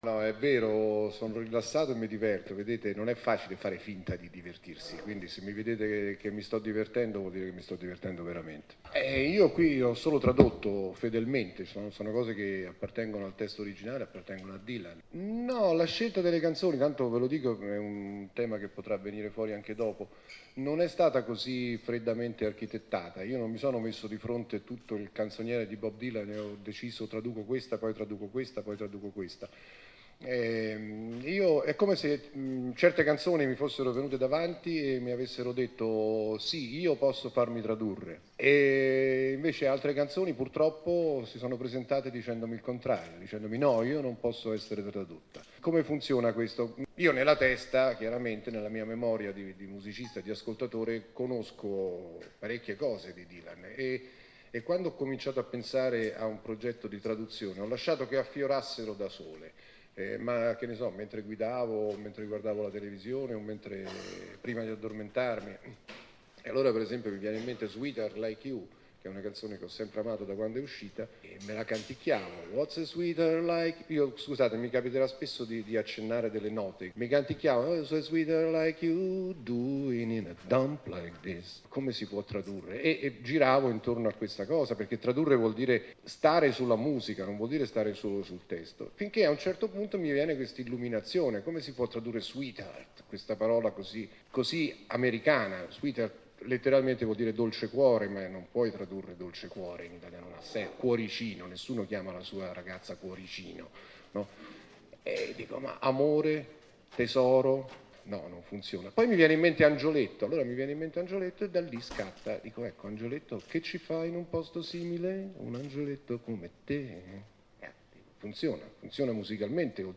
Di passaggio a Milano, prima tappa del suo Instore Tour nelle Librerie Feltrinelli per presentare l’album e prima dei concerti nei teatri previsti nella primavera del 2016, il cantautore romano si è concesso per più di un’ora ai giornalisti spiegando il suo amore per Bob Dylan fin da quando era un ragazzino, le affinità sonore e come nascono le sue canzoni.
Con pignoleria tecnica e chiarezza disarmante, De Gregori ha rivelato i retroscena di un lavoro affascinante come quello di scrivere canzoni e la difficoltà di tradurre un artista della parola e della musica come Dylan.